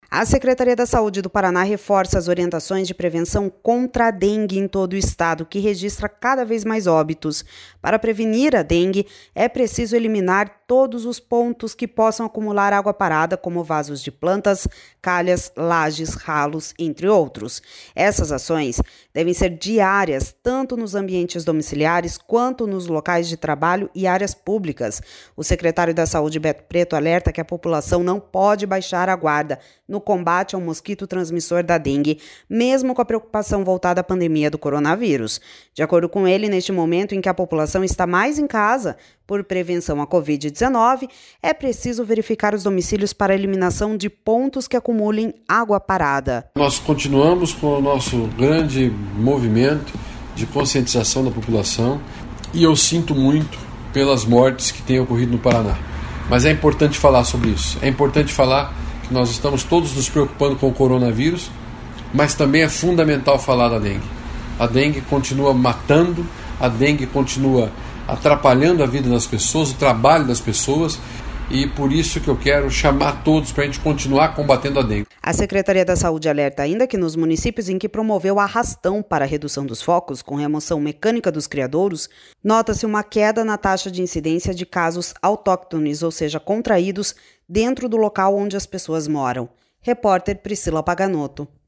O secretário da Saúde, Beto Preto, alerta que a população não pode baixar a guarda no combate ao mosquito transmissor da dengue, mesmo com a preocupação voltada à pandemia do coronavírus. De acordo com ele, neste momento em que a população está mais em casa por prevenção à Covid-19, é preciso verificar os domicílios para a eliminação de pontos que acumulem água parada.// SONORA BETO PRETO//